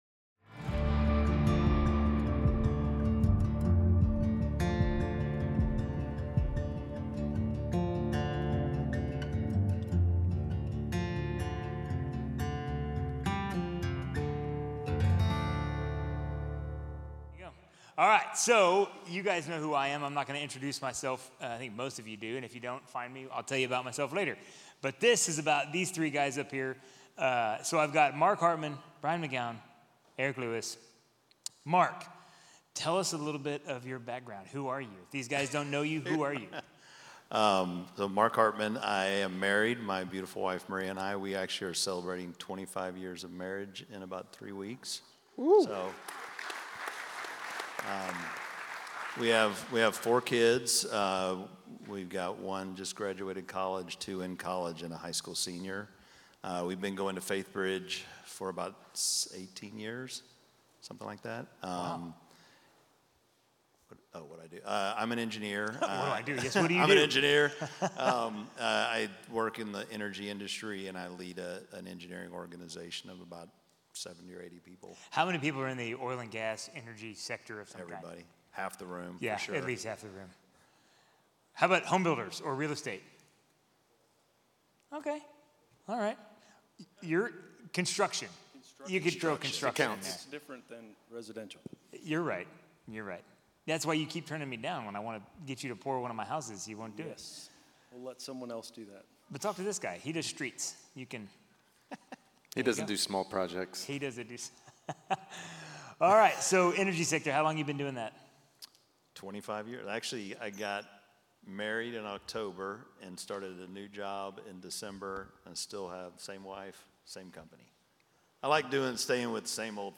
Peace in War - Panel Discussion | Week 4